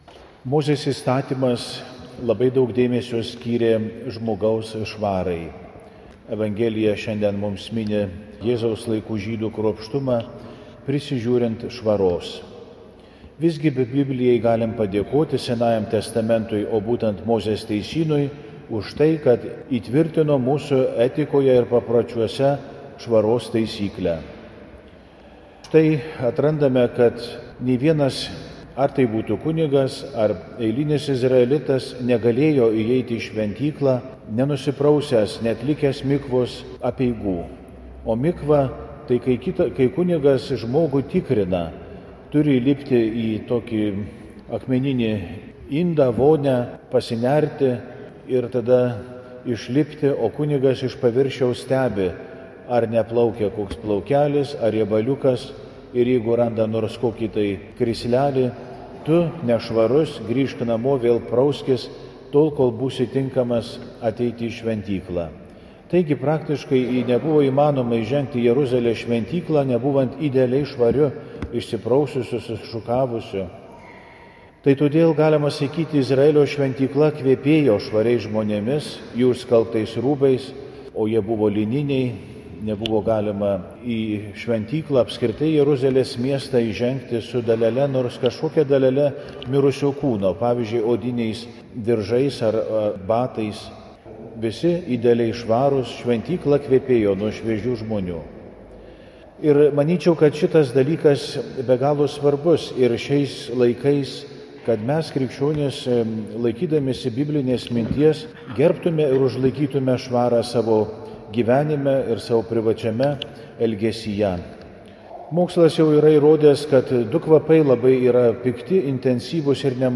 Pamokslai